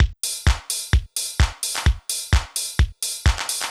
TEC Beat - Mix 3.wav